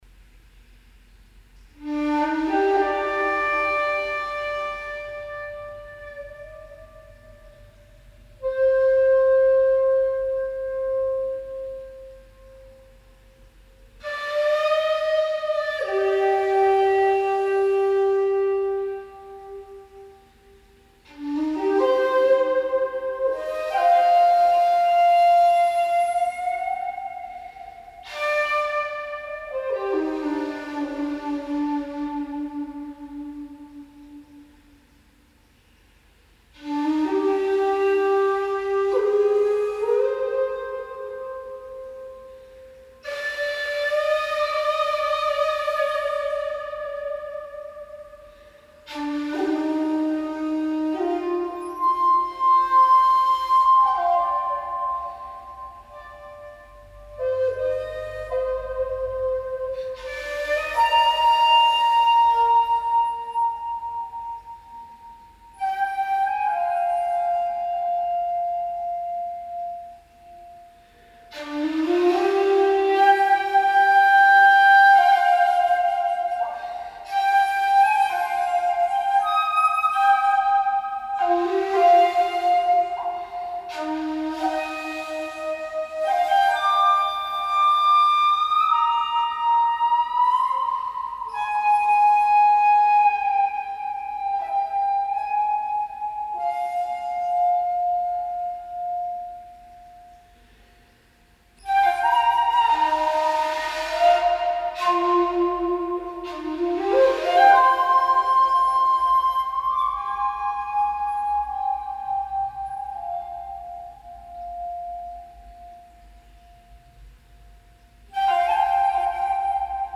Shakuhachi